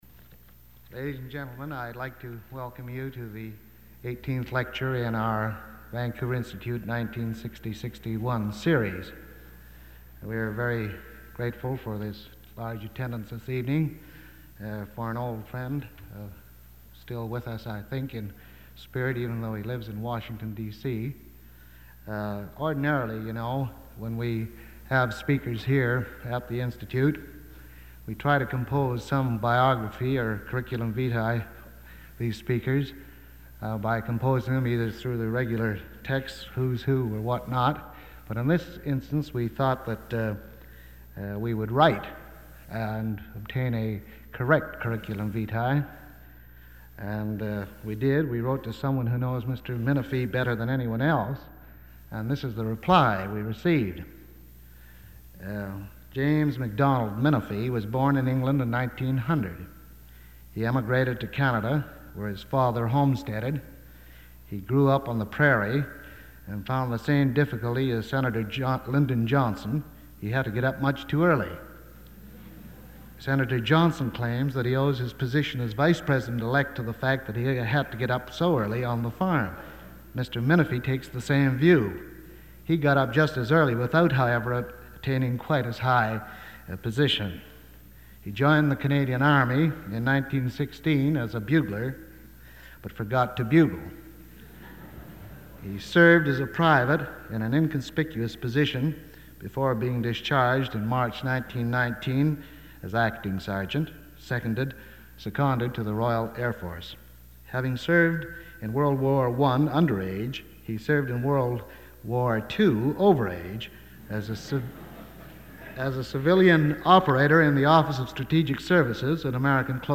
Item consists of a digitized copy of an audio recording of a Vancouver Institute lecture given by James Minifie on March 11, 1961.